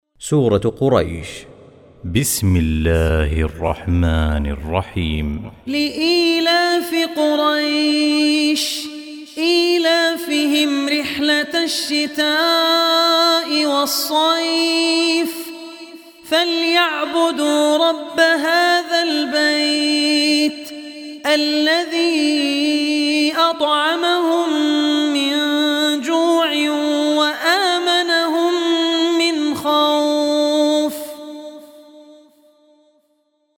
Surah Quraish Recitation